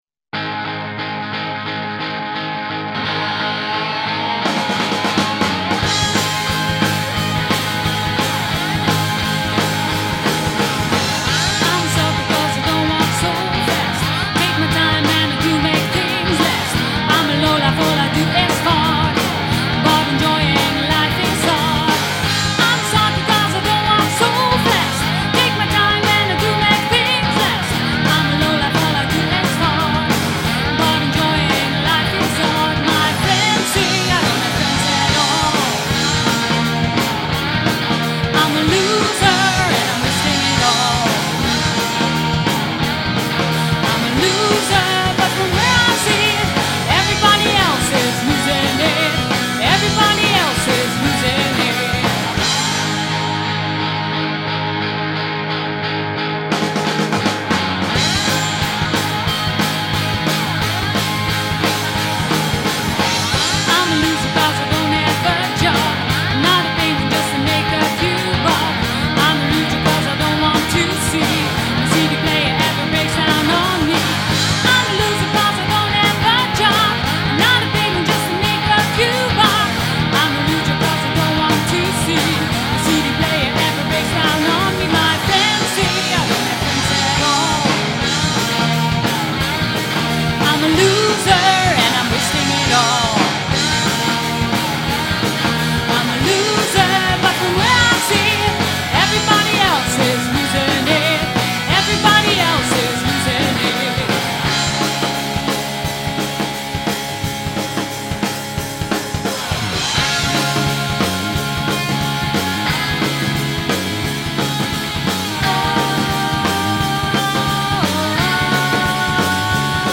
Recorded at Rayment's Hill Studio